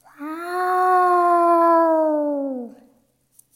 meows-1.mp3